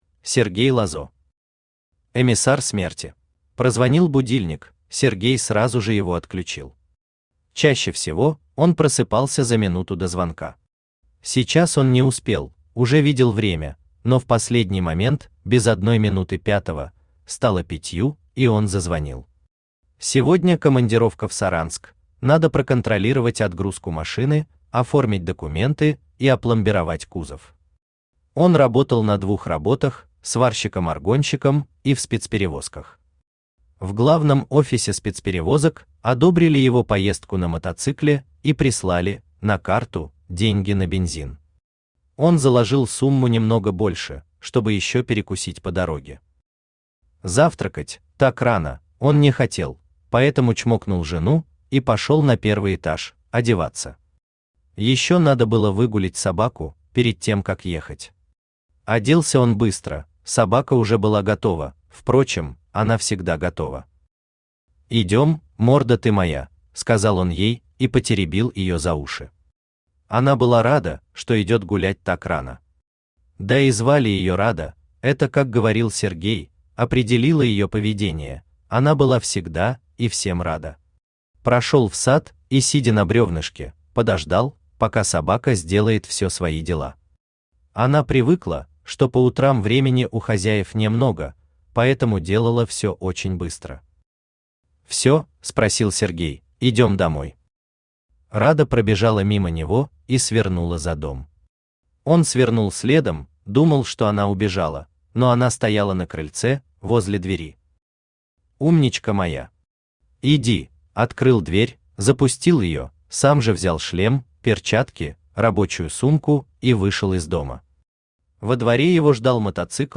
Аудиокнига Эмиссар смерти | Библиотека аудиокниг
Aудиокнига Эмиссар смерти Автор Сергей Лазо Читает аудиокнигу Авточтец ЛитРес.